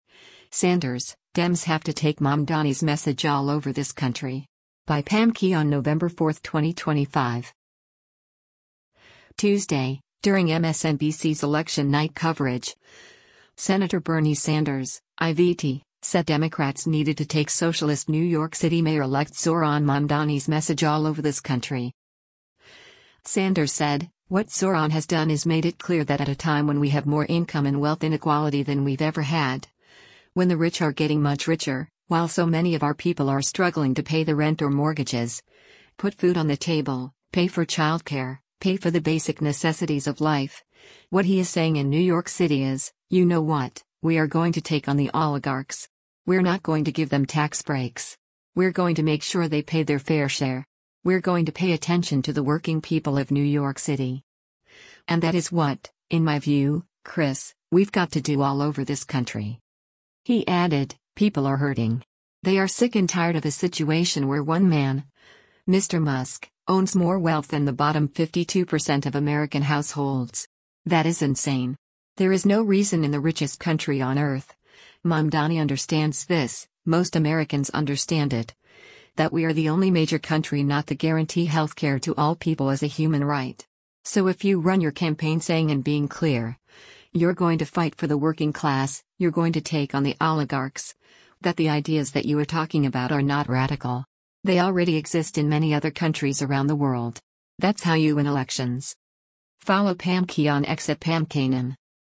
Tuesday, during MSNBC’s election night coverage, Sen. Bernie Sanders (I-VT) said Democrats needed to take socialist New York City Mayor-elect Zohran Mamdani’s message “all over this country.”